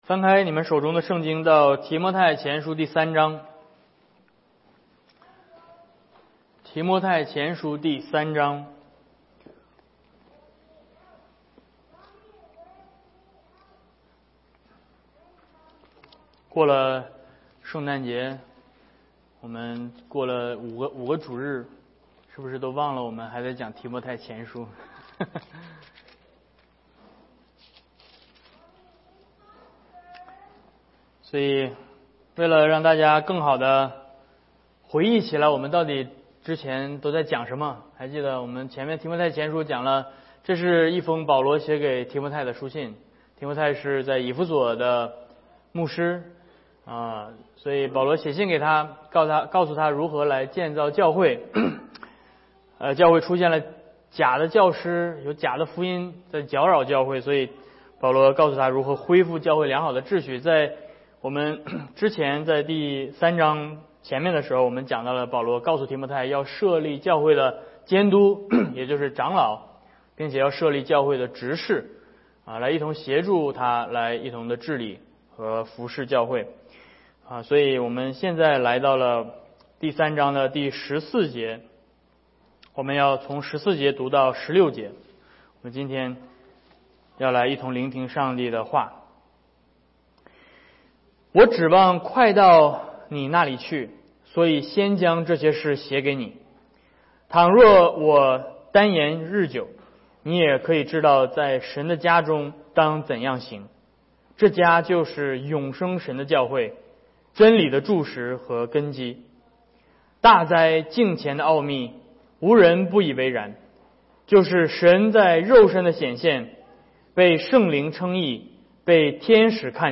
Series: 教牧书信系列 Passage: 1 Timothy 3:14-16 Service Type: 主日讲道 Download Files Notes « 2021圣诞主日